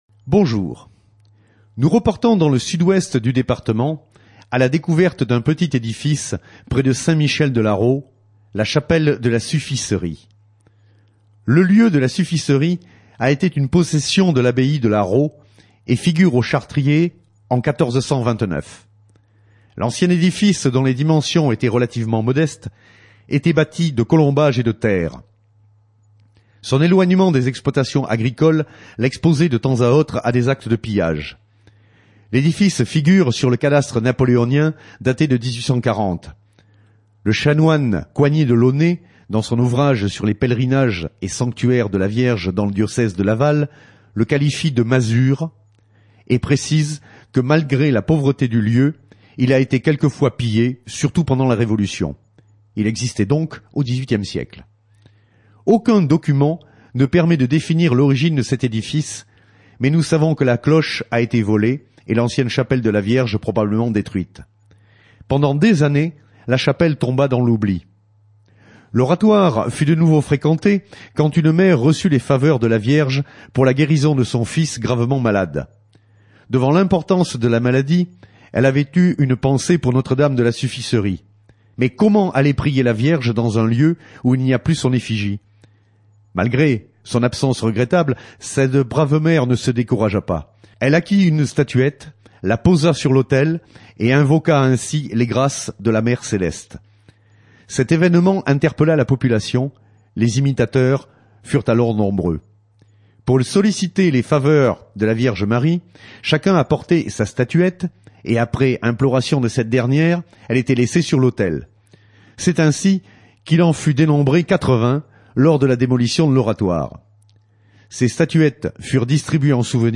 La S.AH.M. sur Radio Fidélité